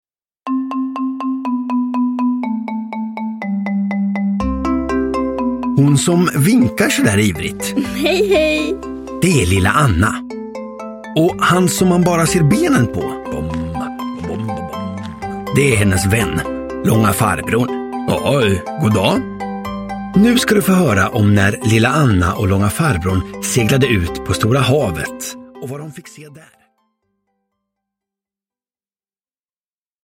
Lilla Anna och Långa farbrorn på havet – Ljudbok – Laddas ner